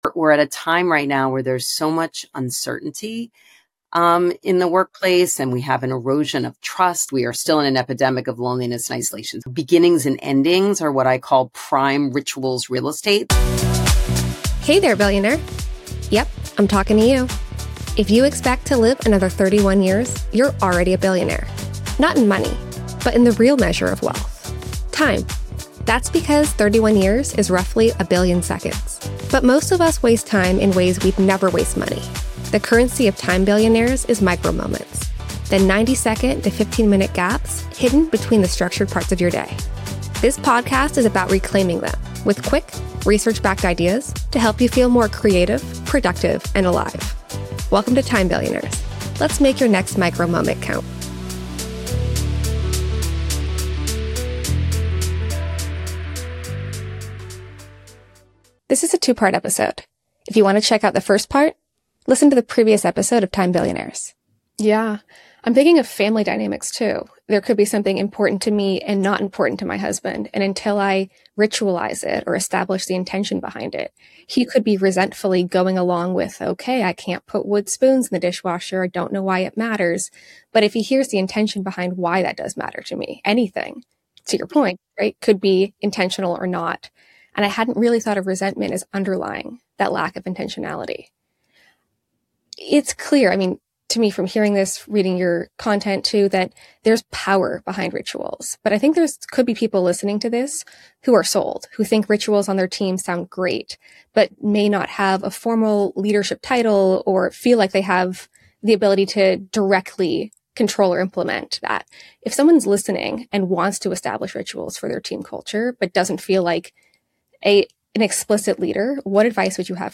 This conversation is for anyone who feels too busy, too disconnected, or too overwhelmed to prioritize connection.